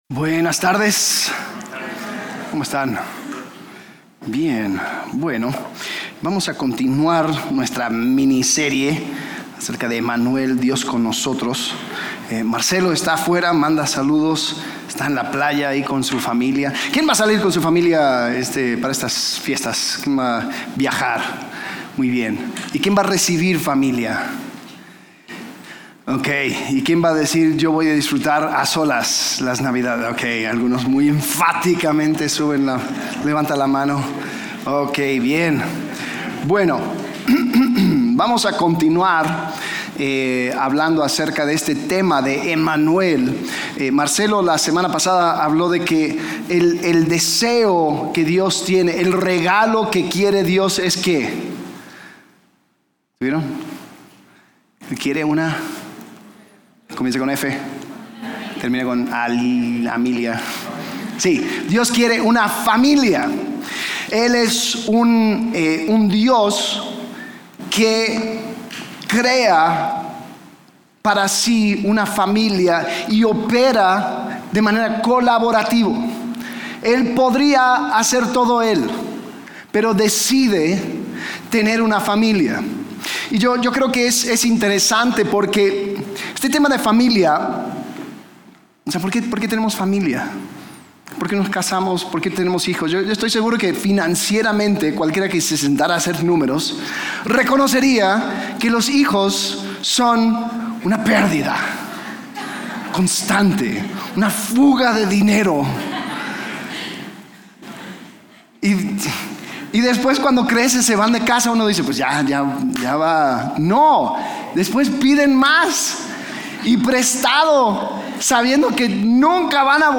Series: Emanuel - El regalo que Dios quiere Servicio: Domingo